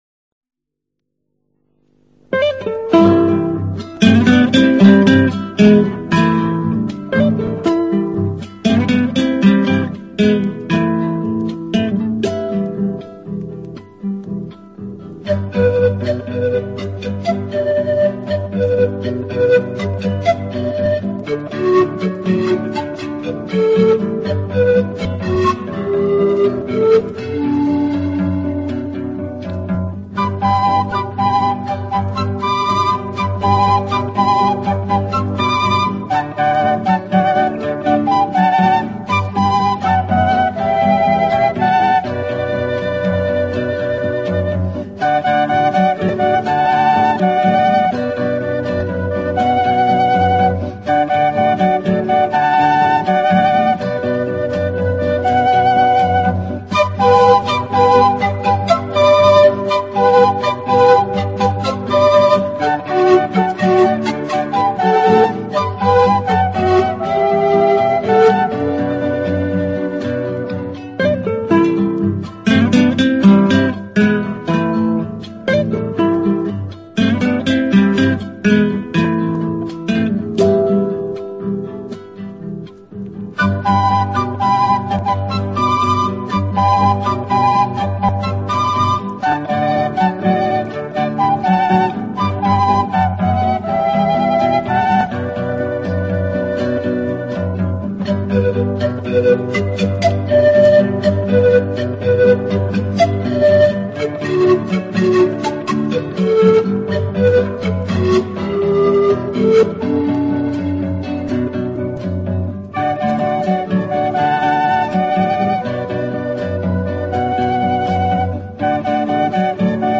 【南美音乐】